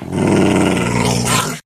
growl3.ogg